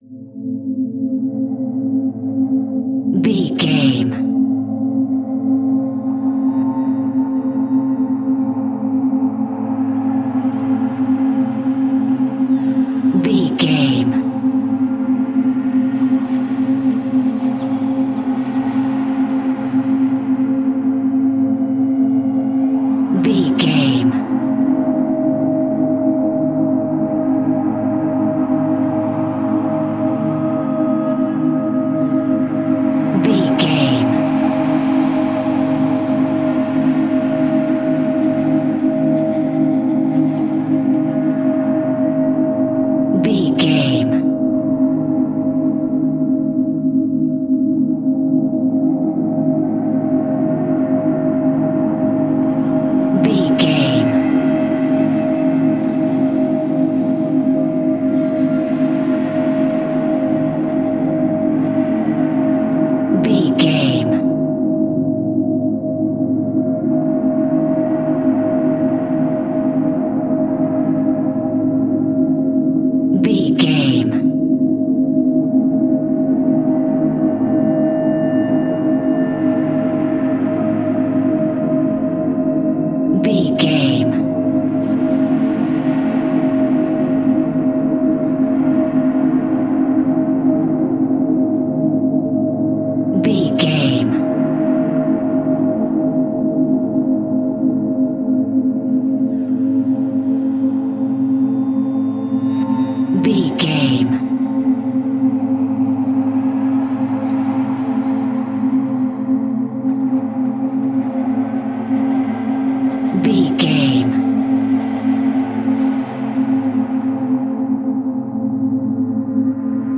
In-crescendo
Thriller
Aeolian/Minor
ominous
suspense
haunting
eerie
horror music
Horror Pads
horror piano
Horror Synths